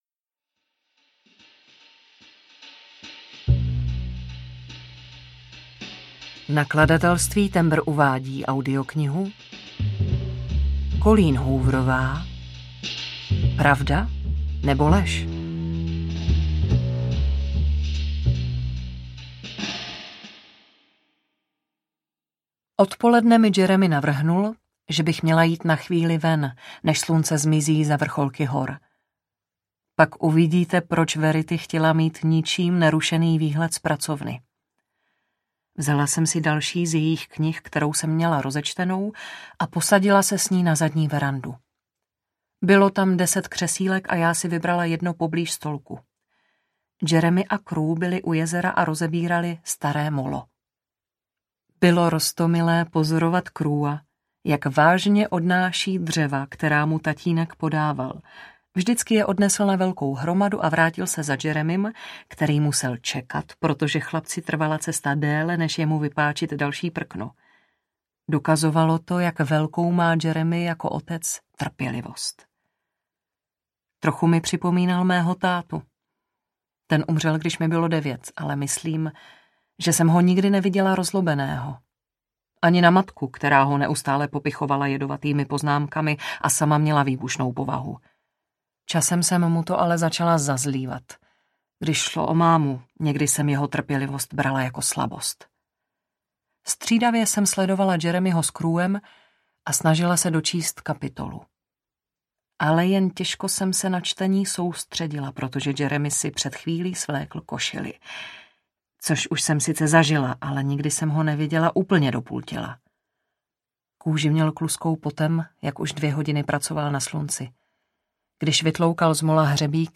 Pravda, nebo lež audiokniha
Ukázka z knihy